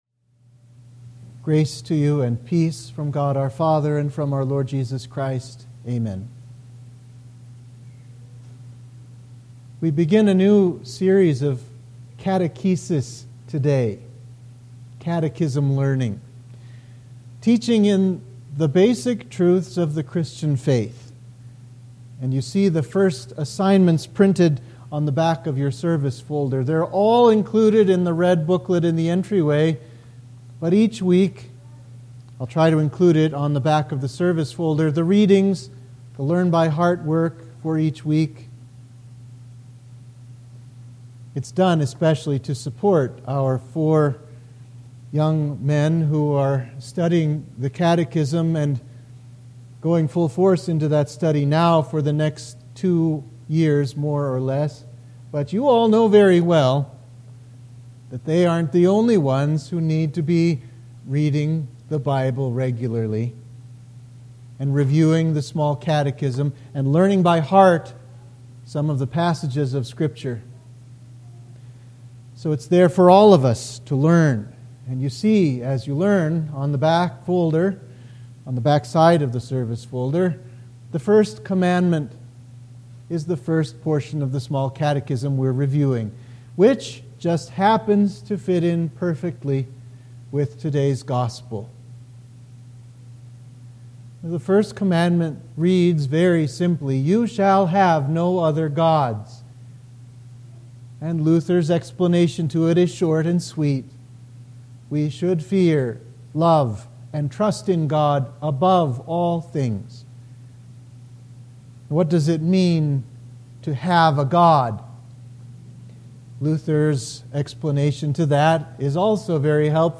Sermon for Trinity 9